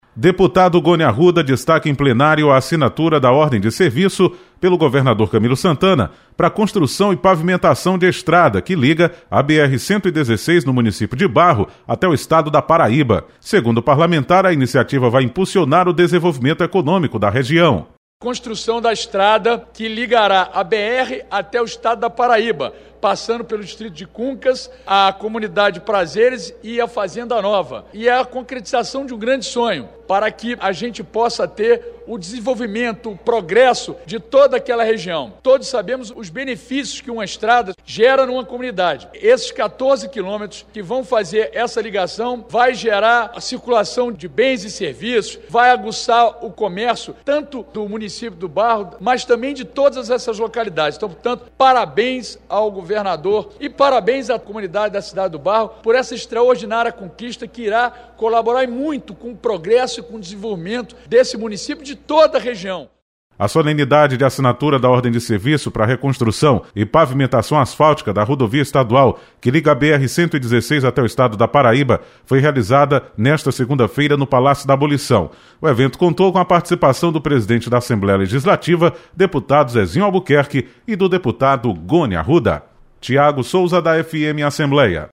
Deputado Gony Arruda destaca  construção  de  estrada que liga município de Barro à Paraíba. Repórter